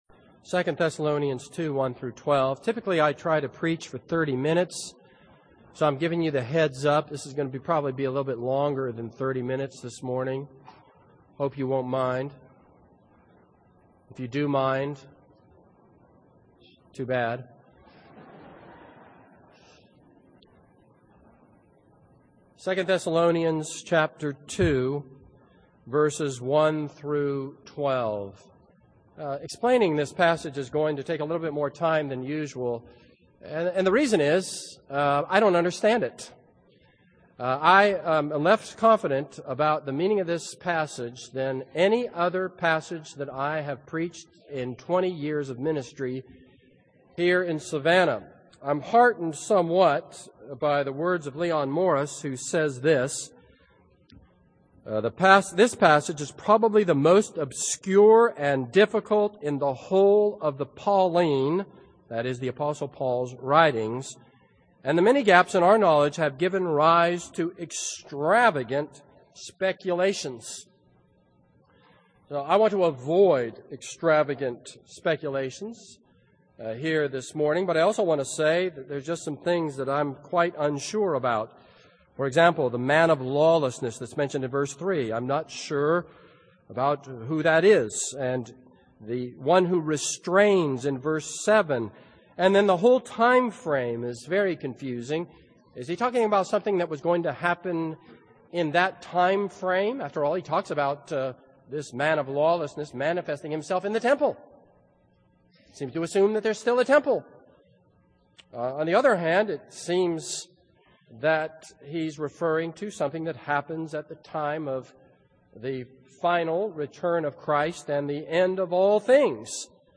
This is a sermon on 2 Thessalonians 2:1-12.